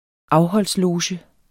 Udtale [ ˈɑwhʌls- ]